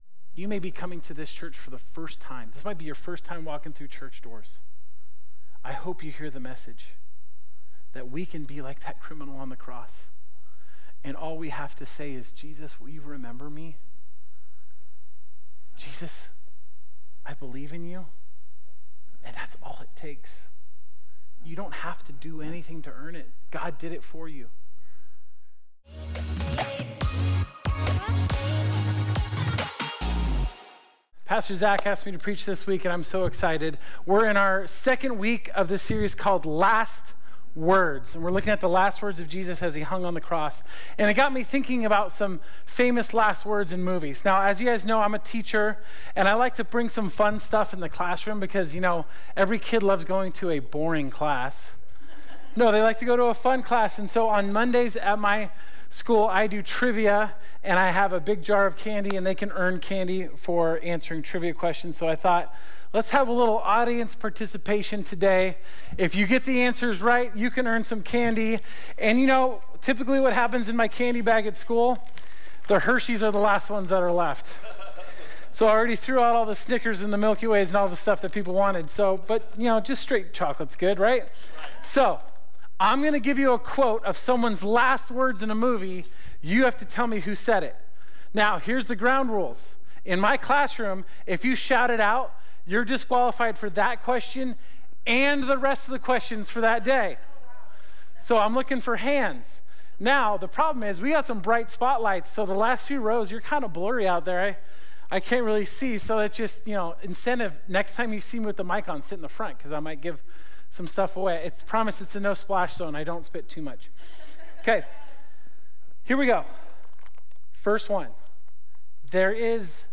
This is part 2 of "Last Words," our sermon series at Fusion Christian Church where we examine the final seven sayings of Jesus before he died.